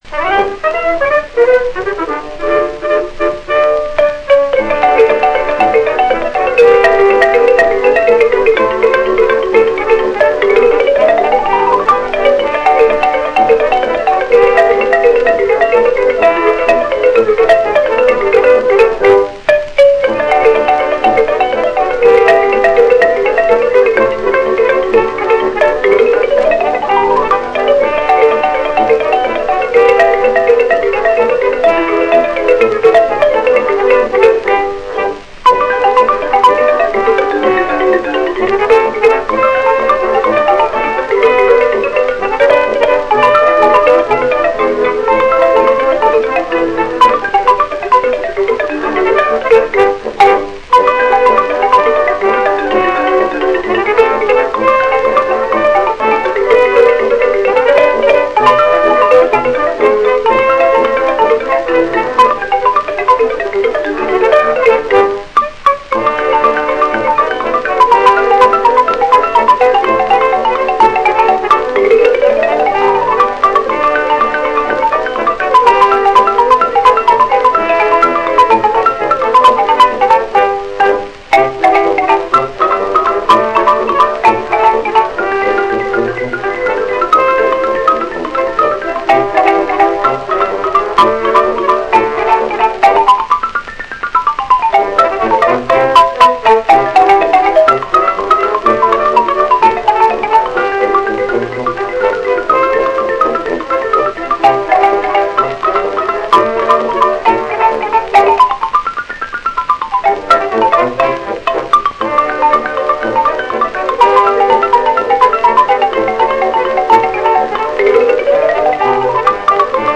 xylophone